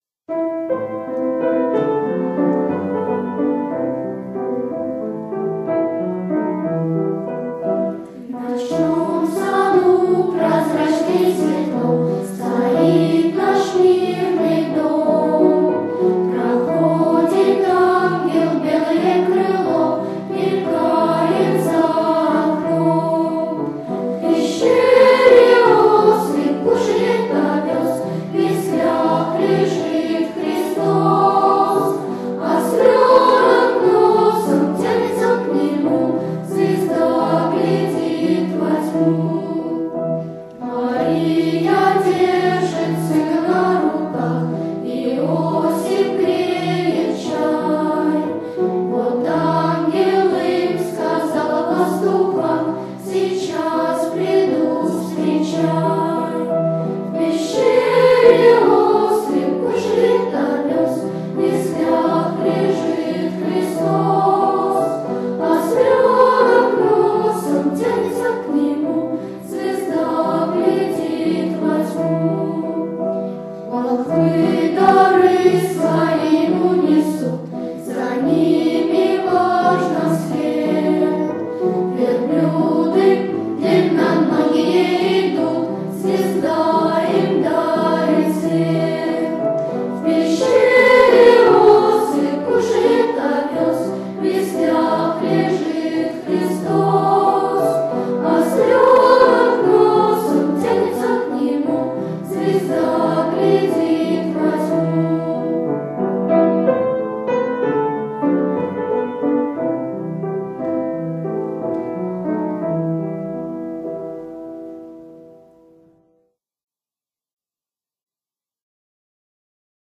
Колядка -Добрий вечір тобі пане господарю!
Исполнитель: Детский хор "Горчичное зёрнышко"